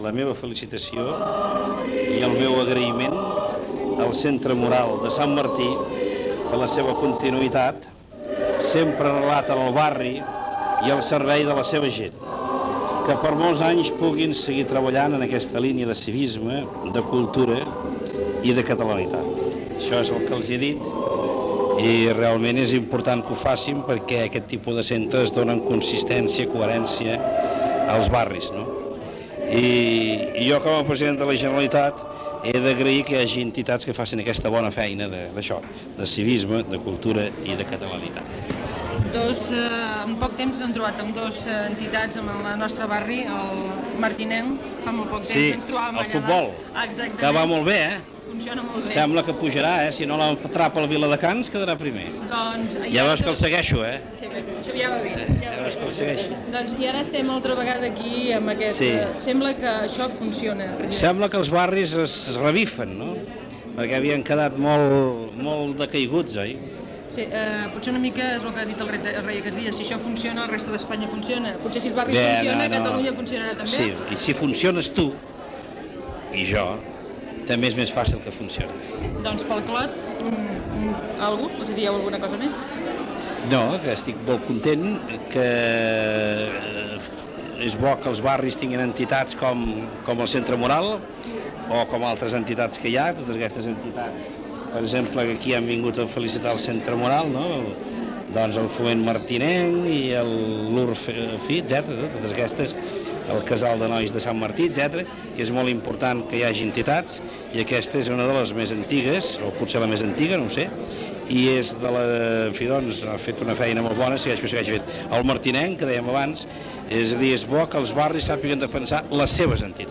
Entrevista al president de la Generalitat Jordi Pujol al Centre Moral de Sant Martí, al barri del Clot de Barcelona